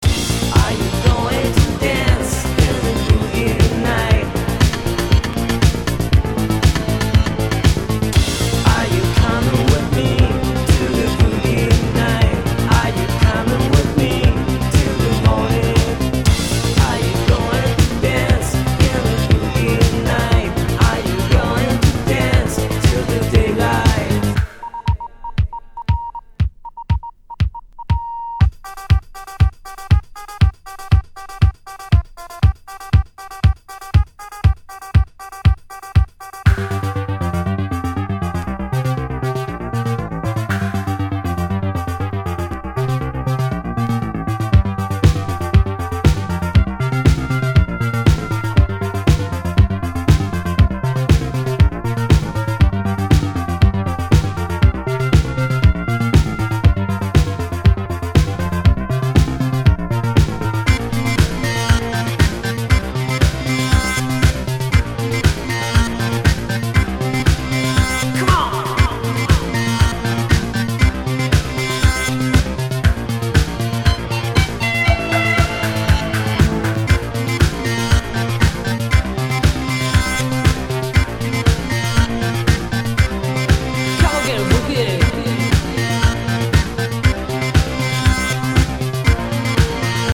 heavily synthetic grooves that refuse to quit